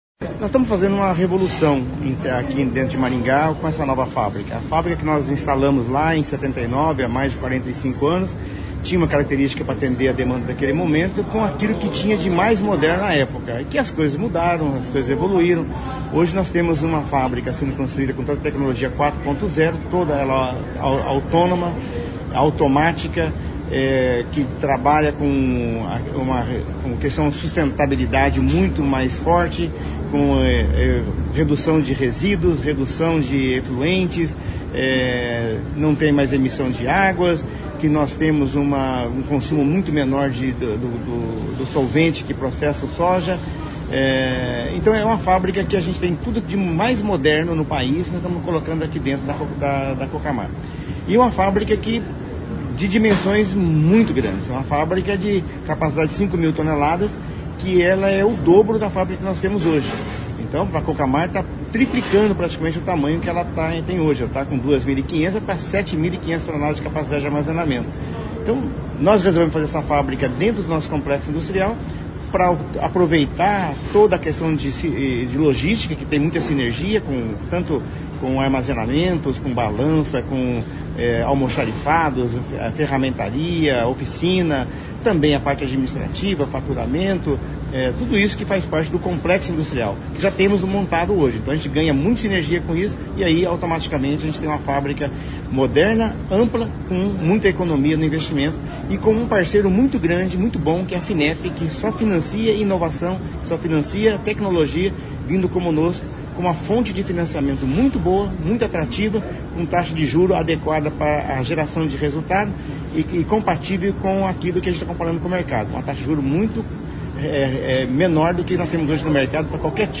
O prefeito Ulisses Maia também esteve presente ao evento de anúncio deste novo investimento. O prefeito falou sobre a importância do agronegócio para o desenvolvimento da cidade.